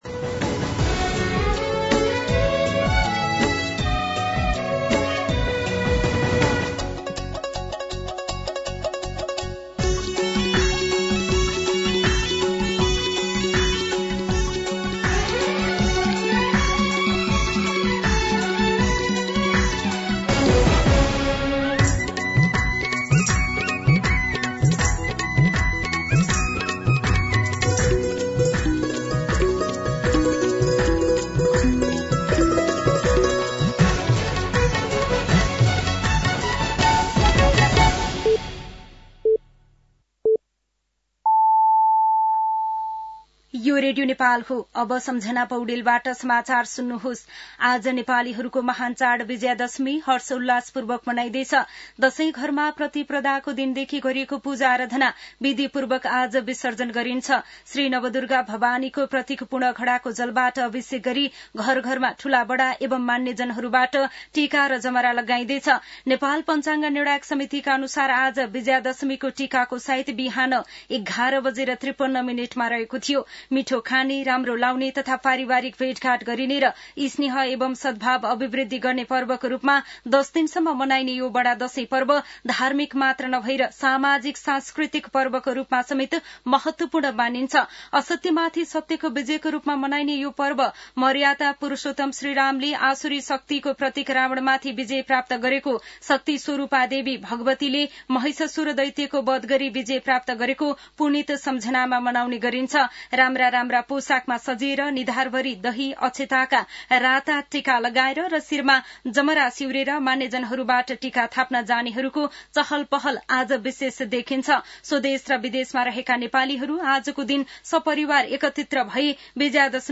मध्यान्ह १२ बजेको नेपाली समाचार : १६ असोज , २०८२